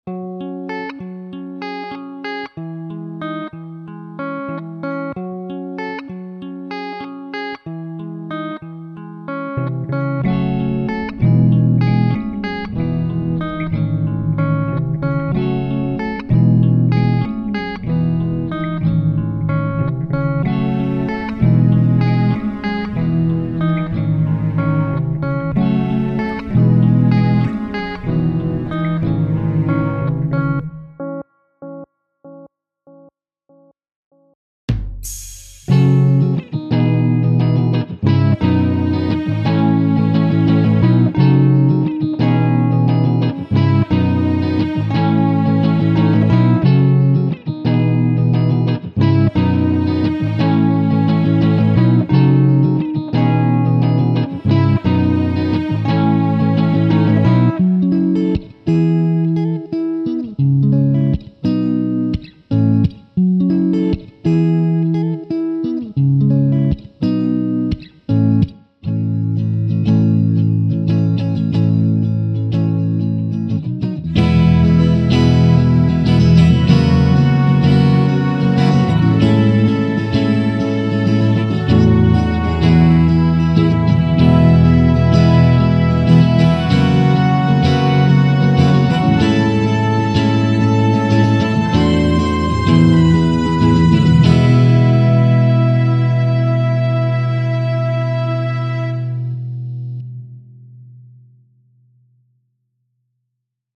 Dit is mijn eerste compositie die ik heb uitgewerkt. Het geeft een contrast weer tussen blijdschap en een mysterieuze sfeer. Vergelijkbaar met een sprookje, het begint met veel veel drama en spanning, maar heeft een compleet einde wat mensen blij maakt.